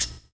Closed Hats
Wu-RZA-Hat 6.wav